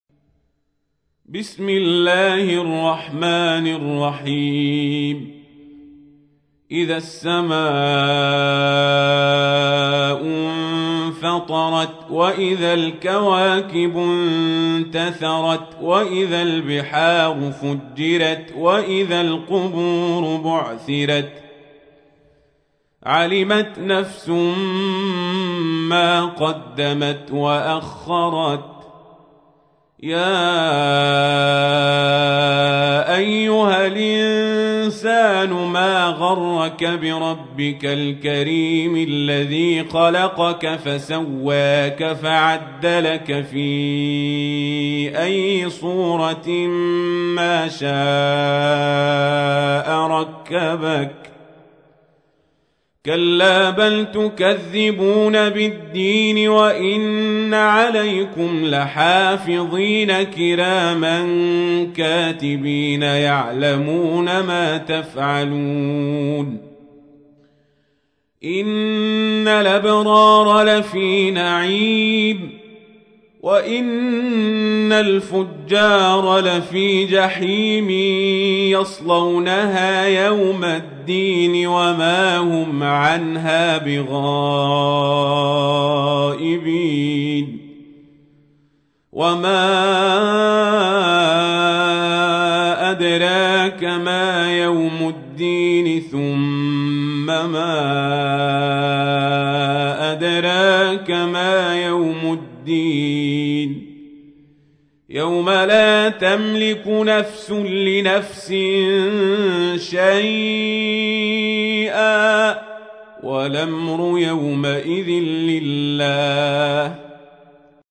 تحميل : 82. سورة الانفطار / القارئ القزابري / القرآن الكريم / موقع يا حسين